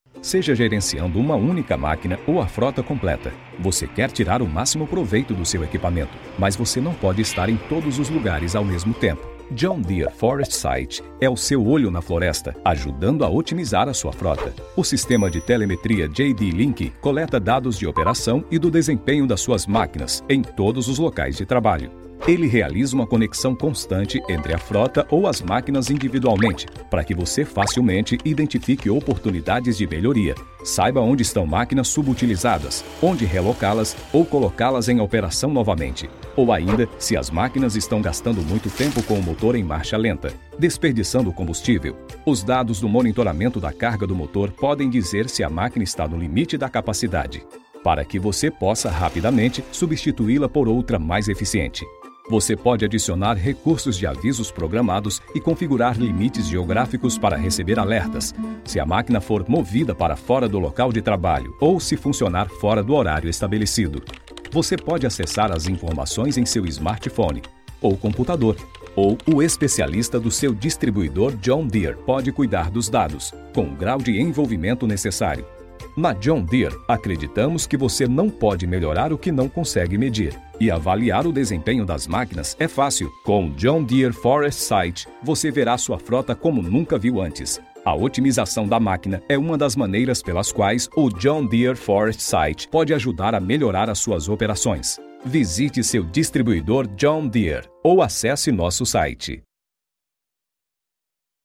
Male
Yng Adult (18-29), Adult (30-50)
Narration
Explainer Videos